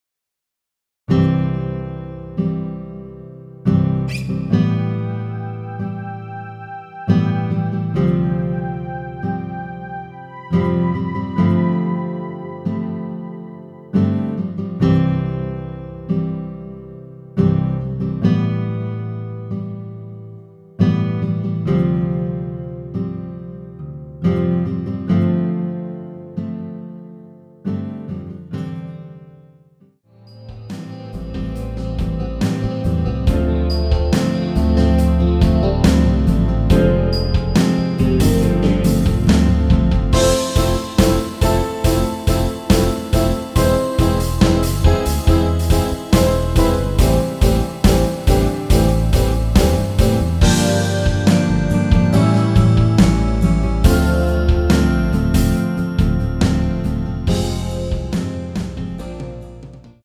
◈ 곡명 옆 (-1)은 반음 내림, (+1)은 반음 올림 입니다.
앞부분30초, 뒷부분30초씩 편집해서 올려 드리고 있습니다.